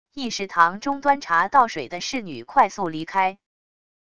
议事堂中端茶倒水的侍女快速离开wav音频